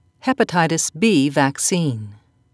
(hep'a-ti-tis)